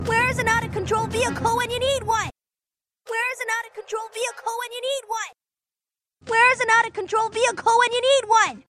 stumbled upon somebody doing the same as me and found some INCREDIBLY well cleaned up segment that were initially clogged up in BG music and seemed unusable.
The first one is the original, the second my attempt and the third his attempt.